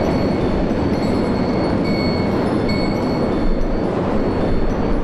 abjuration-magic-sign-circle-loop.ogg